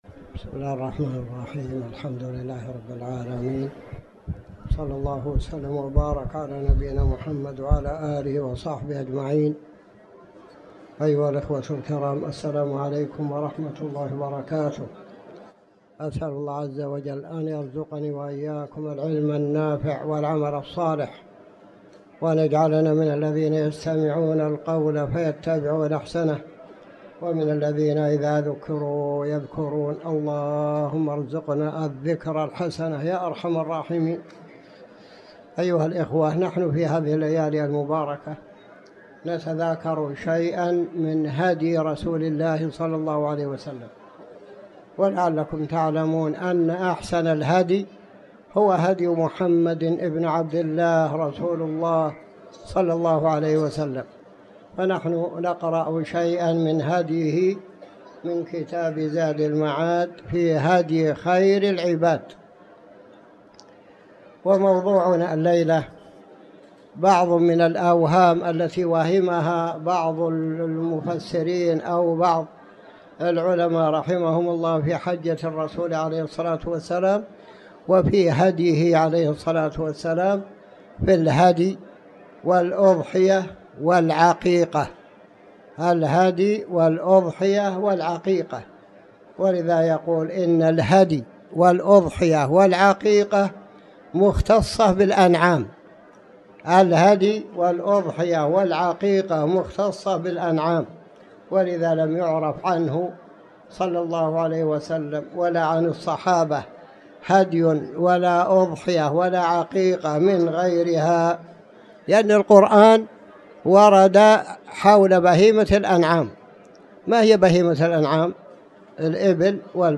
تاريخ النشر ٢٠ جمادى الأولى ١٤٤٠ هـ المكان: المسجد الحرام الشيخ